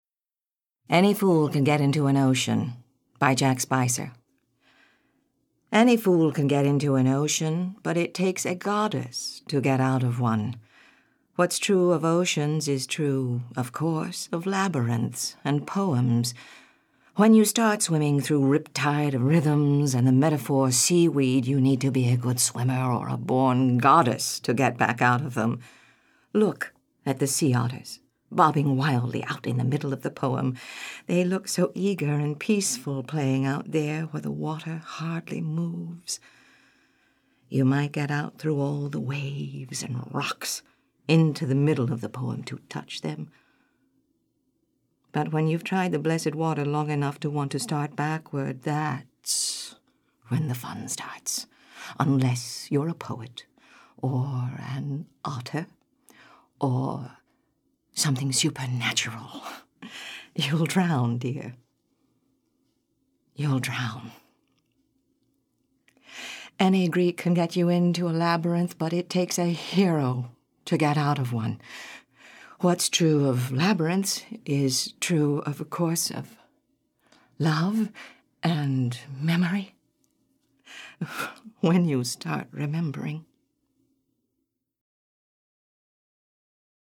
In celebration of National Poetry Month, every day we're posting a new poem from the spoken-word album Poetic License, a three-CD set that features one hundred performers of stage and screen reading one hundred poems selected by the actors themselves.
Harriet Harris is a native Texan actress who has appeared in Broadway productions of Cry-Baby, Thoroughly Modern Millie, and The Man Who Came to Dinner, as well as on television in Desperate Housewives and Frasier.